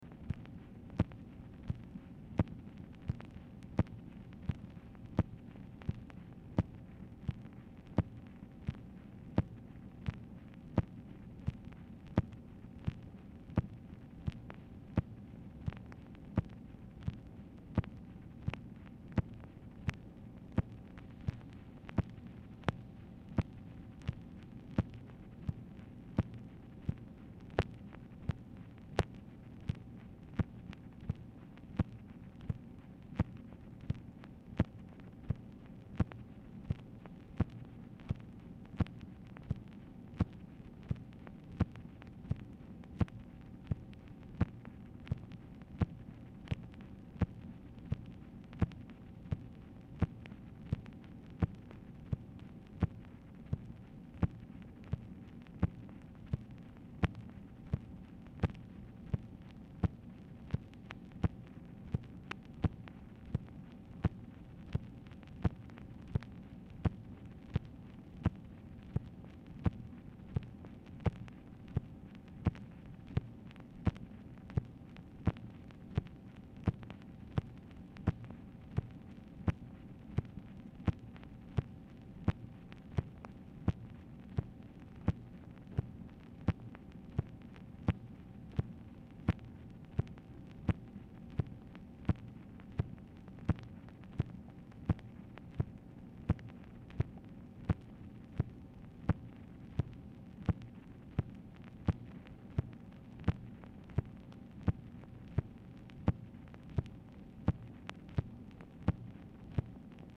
Telephone conversation # 9425, sound recording, MACHINE NOISE, 1/4/1966, time unknown | Discover LBJ
Format Dictation belt
White House Telephone Recordings and Transcripts Speaker 2 MACHINE NOISE